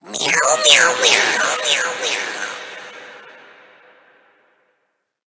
Cri de Miaouss Gigamax dans Pokémon HOME.
Cri_0052_Gigamax_HOME.ogg